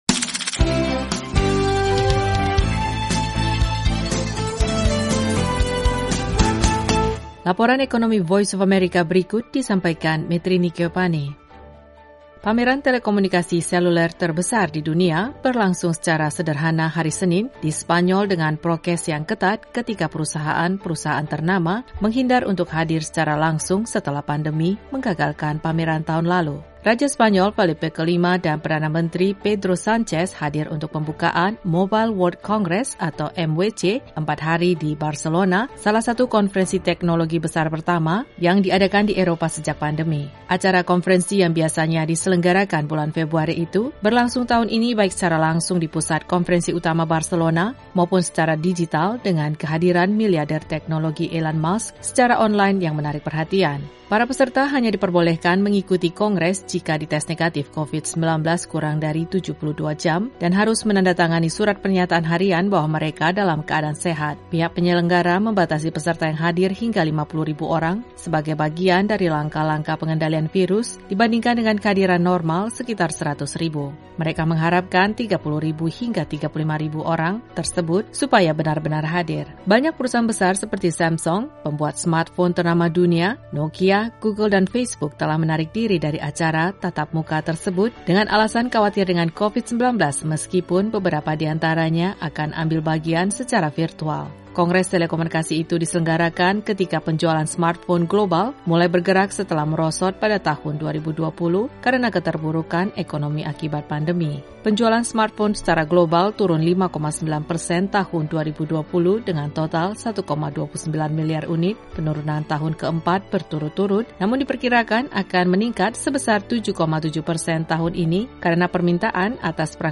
Laporan Ekonomi VOA hari ini mengenai kongres telekomunikasi seluler digelar di Spanyol dengan prokes yang ketat. Simak juga informasi terkait Uni Eropa yang menyetujui undang-undang untuk netral karbon tahun 2050.